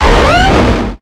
Grito de Garchomp.ogg
Grito_de_Garchomp.ogg